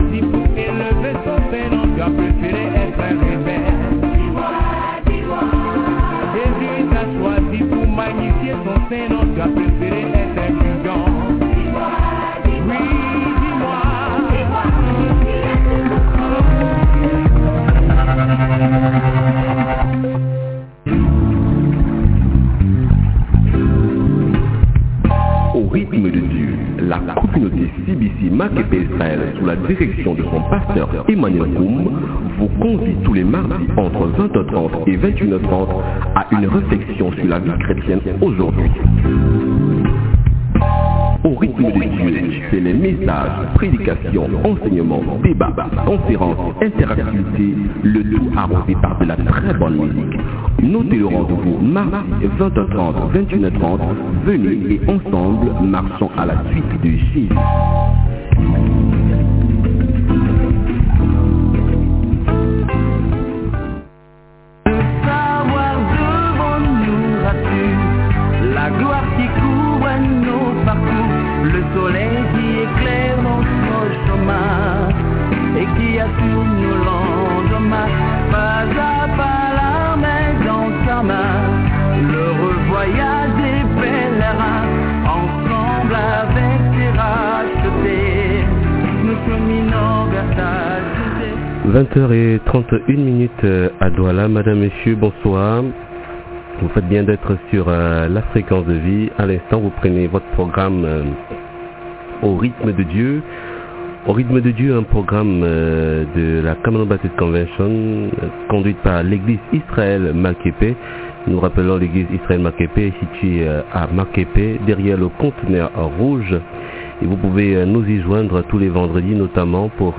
Evangeliste